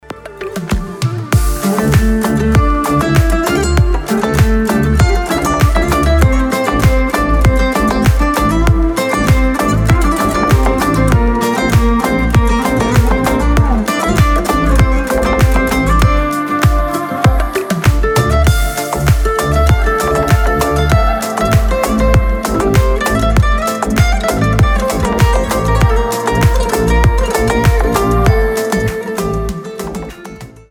• Качество: 320, Stereo
гитара
без слов
красивая мелодия